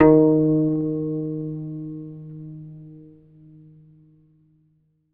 ZITHER D#1.wav